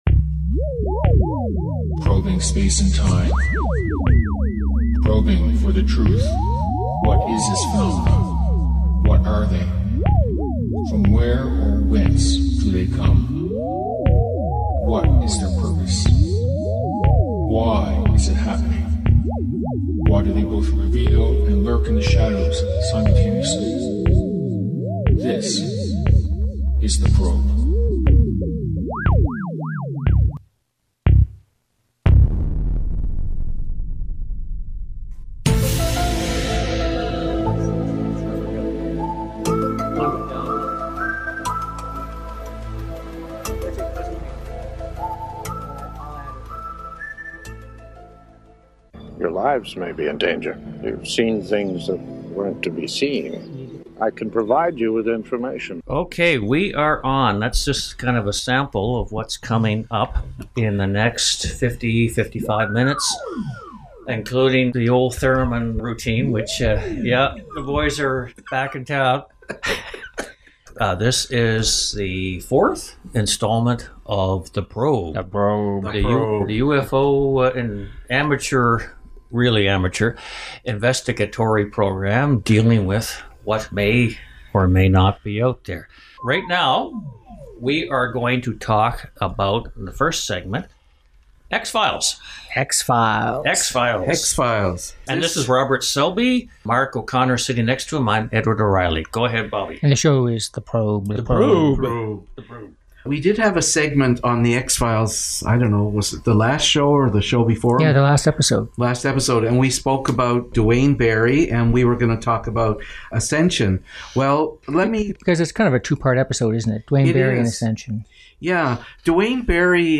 UFO Talk Show